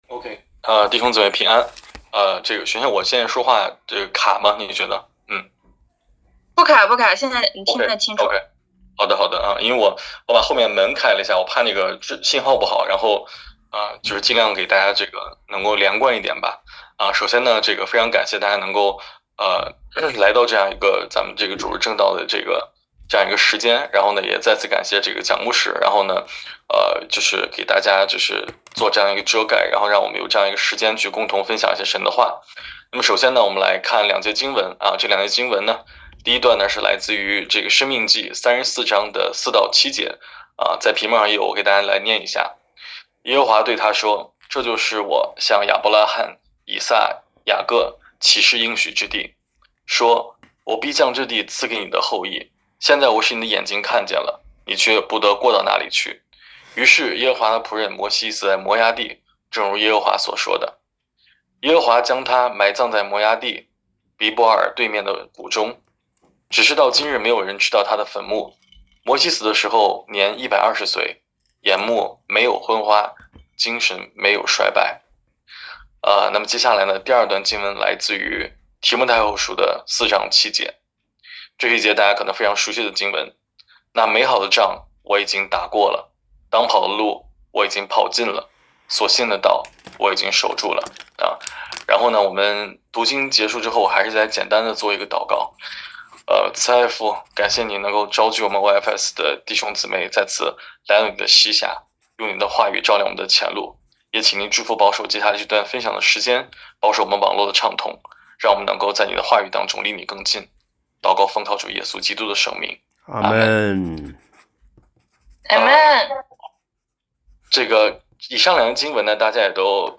2024年8月14日主日